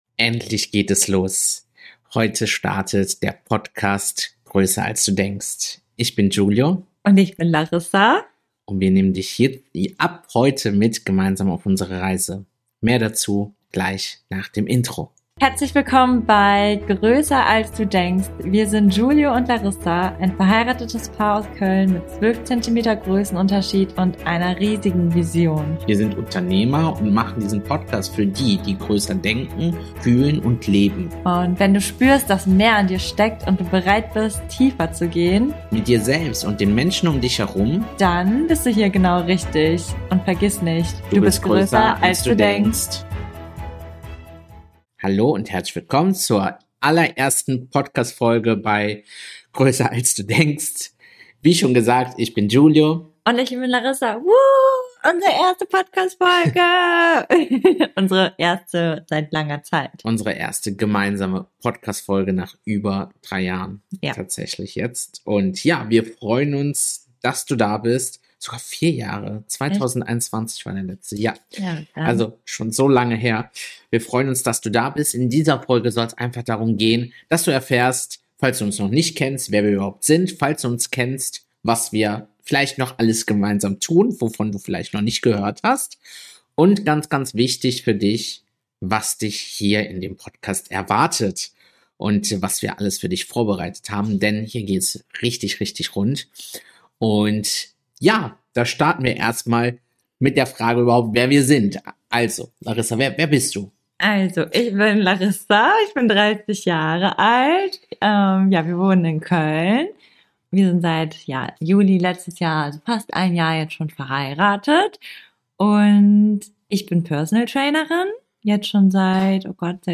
Sondern echte, authentische Gespräche – ungeschnitten, ehrlich, tief.